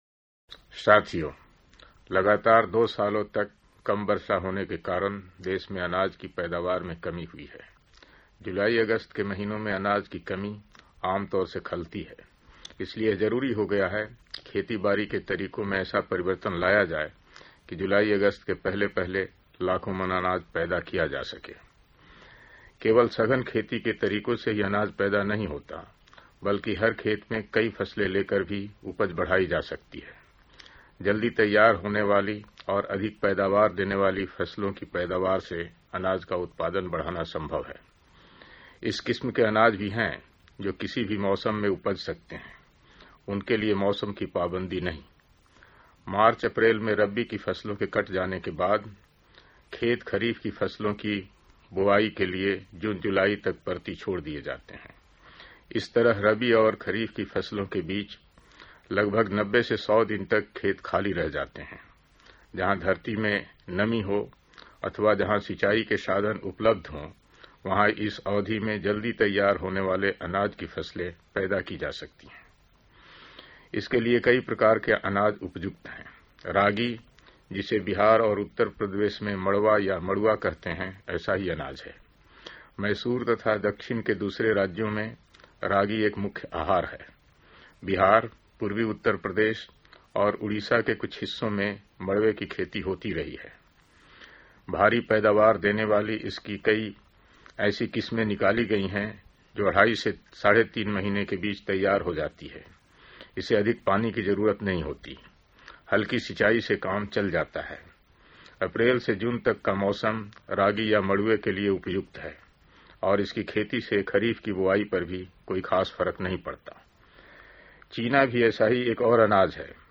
Audio speeches of Babu Jagjivan Ram outside Parliament
Message by Jagjivan Ram-Union Minister 11-4-68